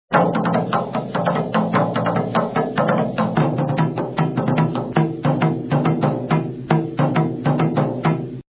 tambourfleur.mp3